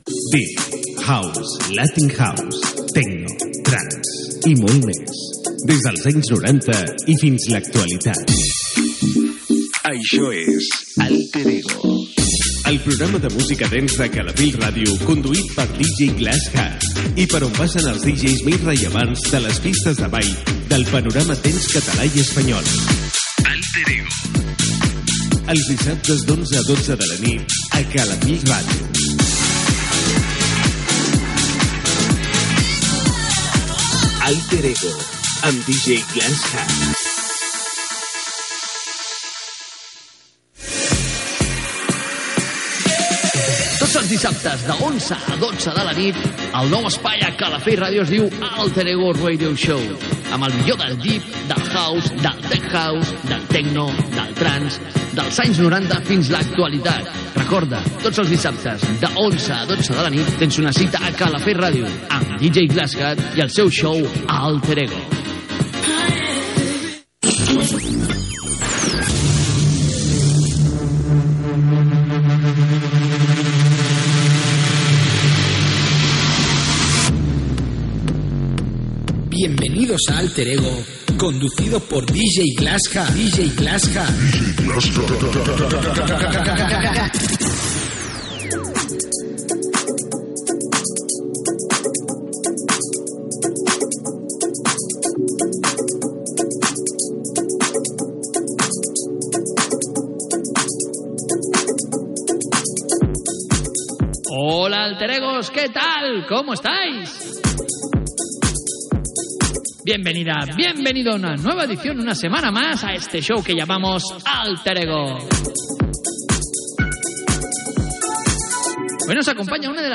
Careta i inici del programa de música "dance" i dj's.
Musical